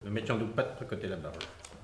Elle provient de Saint-Gervais.
Locution ( parler, expression, langue,... )